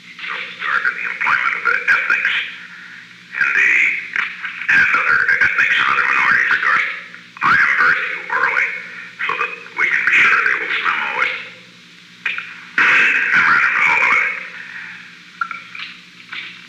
Conversation No. 917-14
Location: Oval Office
The President played portions of a previously recorded dictabelt tape.